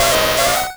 Cri de Ponyta dans Pokémon Rouge et Bleu.